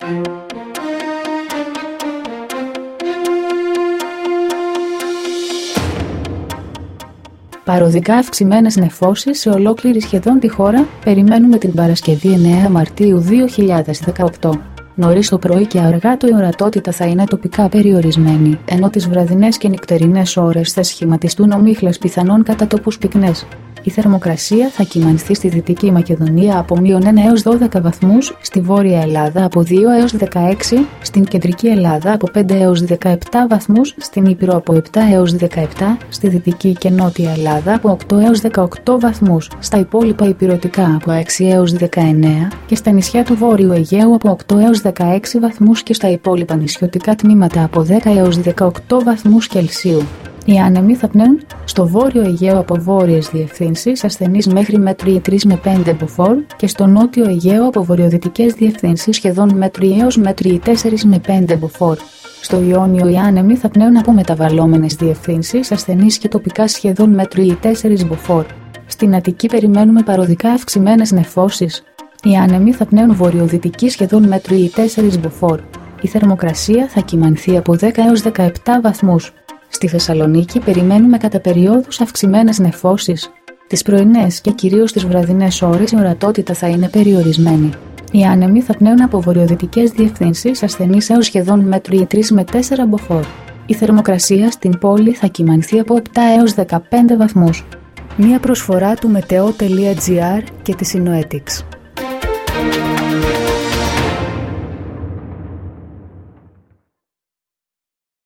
dailyforecastddd.mp3